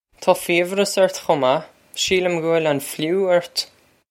Taw feev-rus urt khoh mah. Sheel-uhm guh wil on flu urt.
This is an approximate phonetic pronunciation of the phrase.